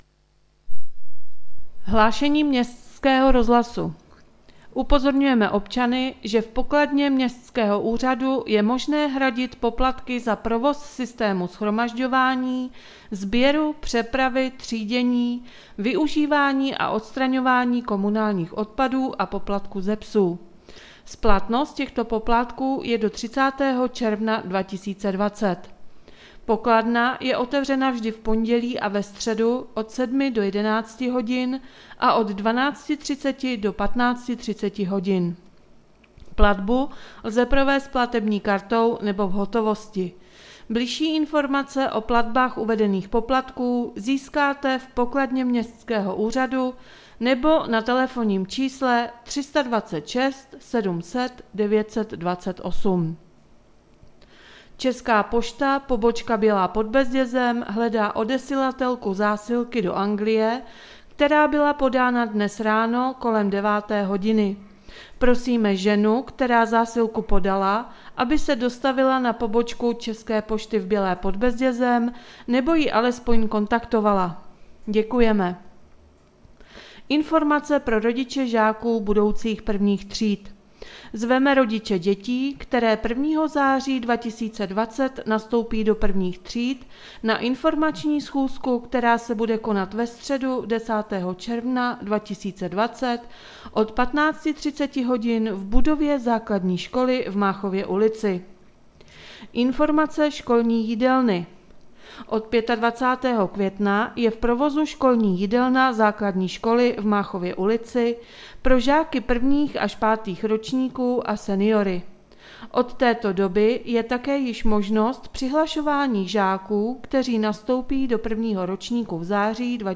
Hlášení městského rozhlasu 8.6.2020